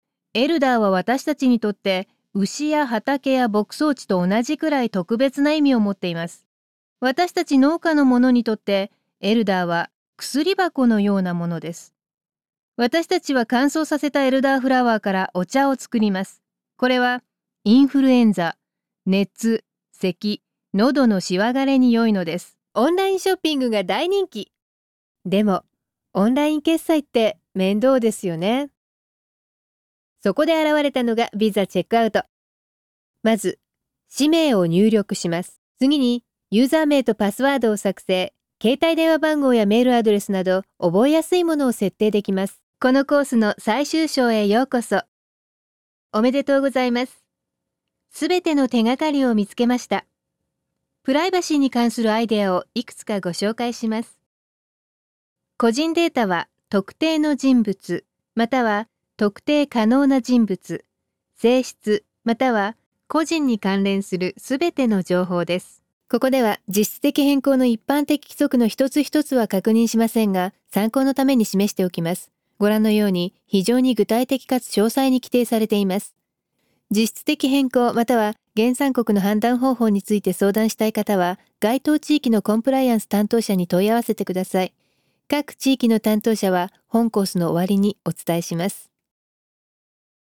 japanese,voiceover,narration,animation,corporate,video,advertising,character voices,voice over,broadcasting
Sprechprobe: Industrie (Muttersprache):